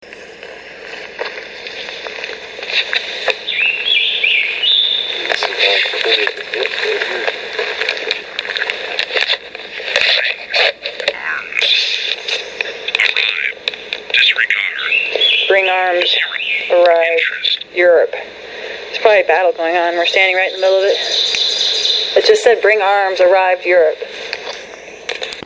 These 2 audio clips are of the Ovilus. While we were hiking it appears we were picking up on Revolutionary War talk.